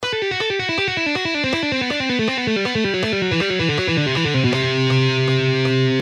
Cascading style guitar licks
Lesson 2: Legato Exercise In Cascade – Upper Harmony
Listen the lick in original speed: